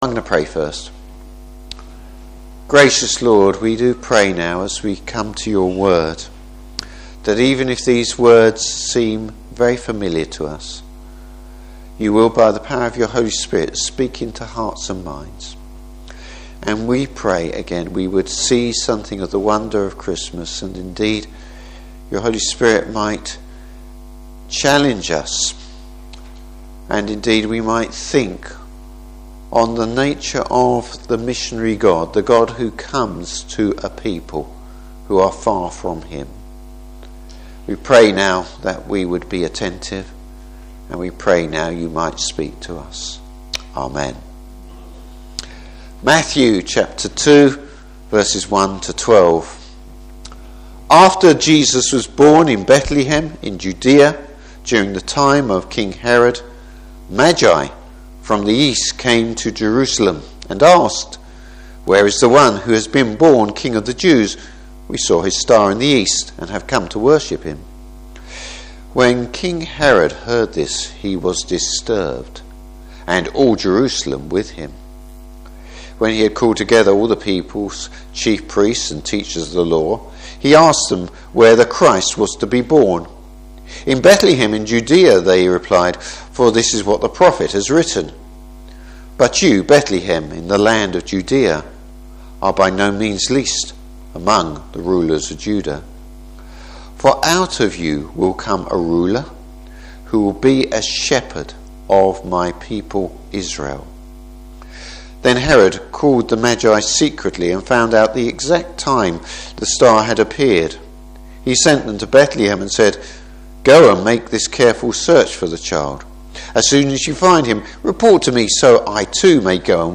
Service Type: Christmas Day Morning Service.